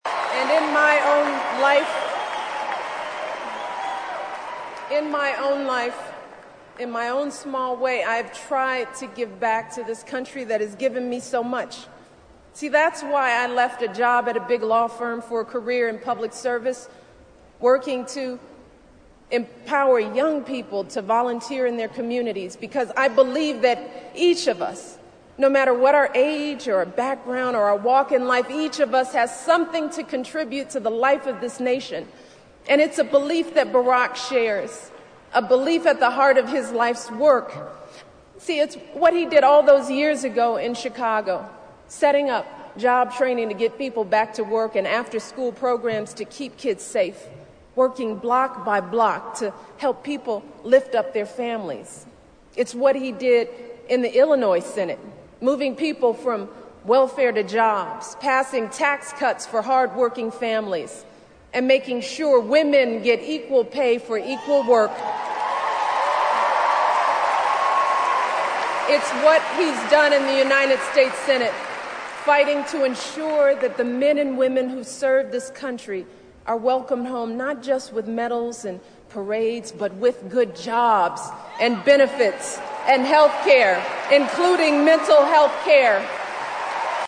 名人励志英语演讲 第94期:让我们选举巴拉克·奥巴马为美利坚合众国总统(7) 听力文件下载—在线英语听力室